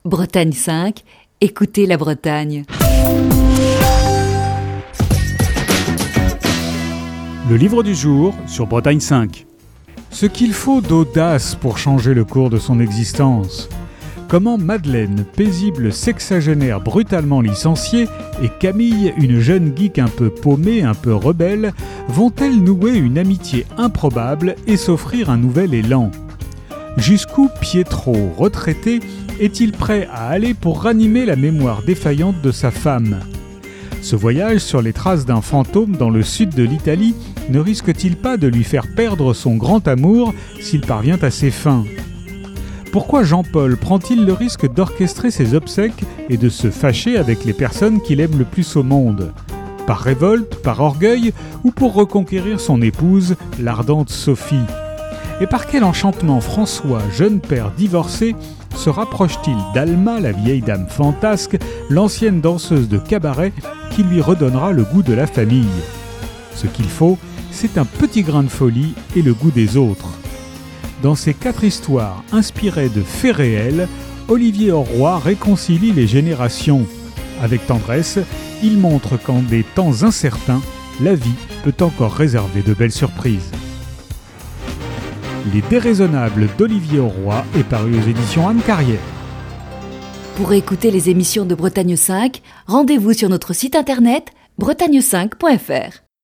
Chronique du 20 mai 2021.